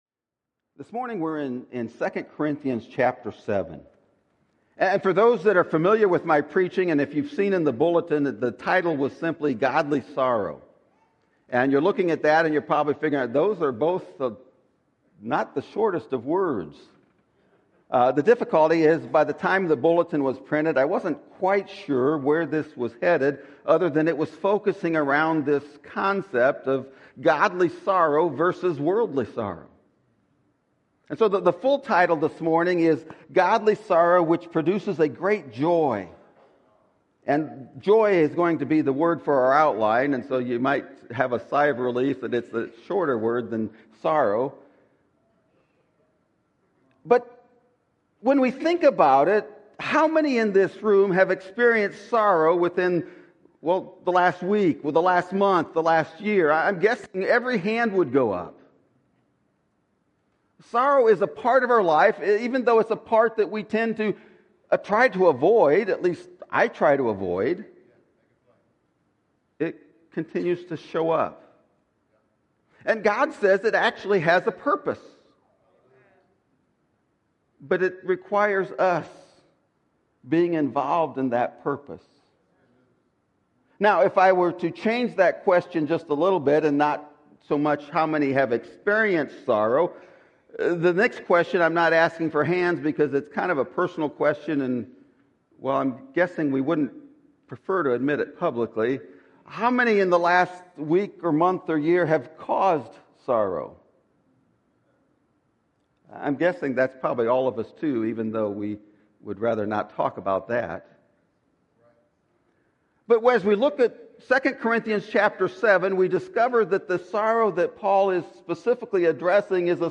Godly Sorrow Which Produces A Great JOY! (Sermon Audio)